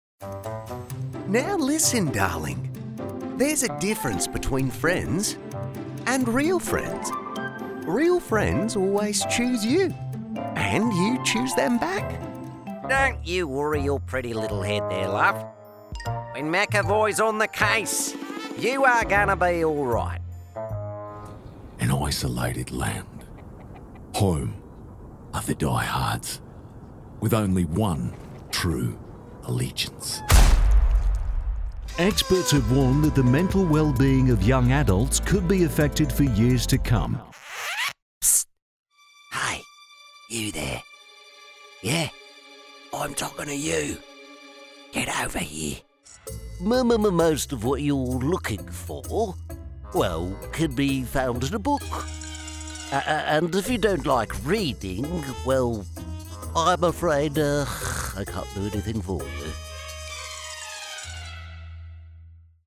Demo
Young Adult, Adult